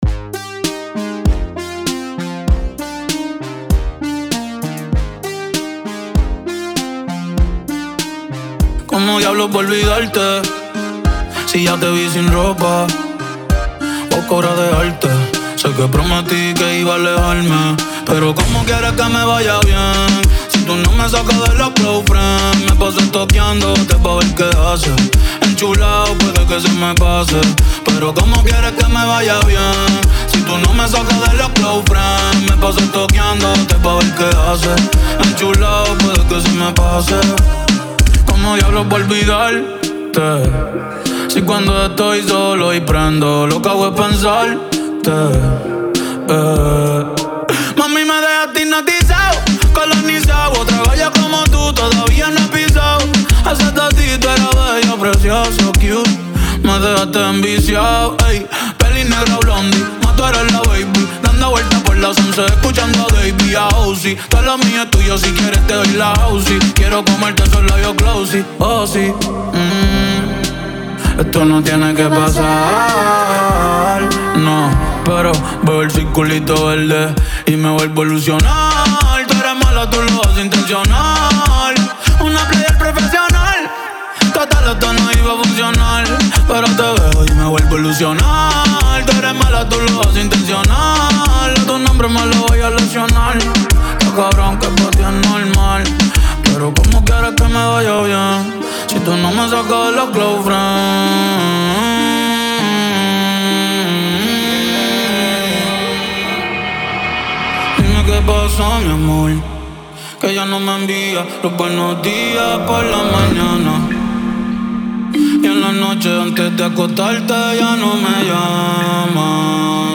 98-100 bpm